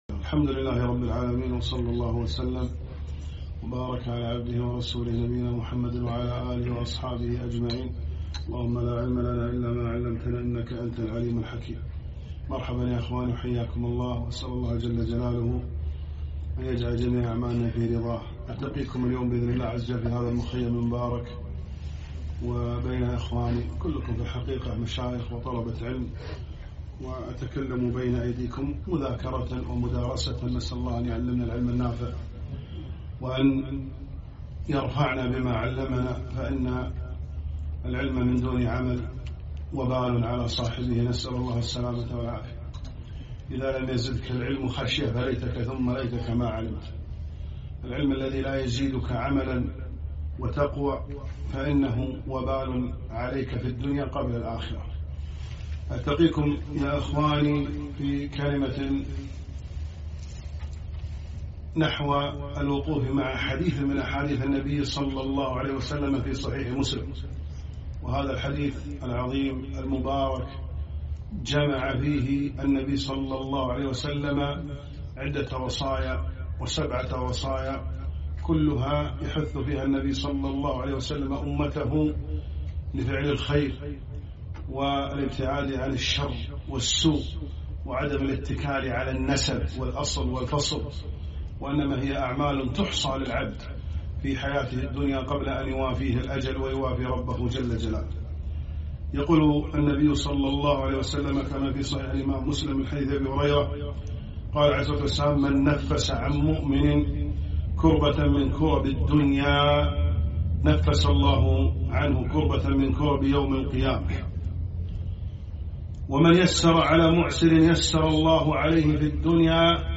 محاضرة - وقفات مه حديث النبي ﷺ (من نفس عن مؤمن كربة)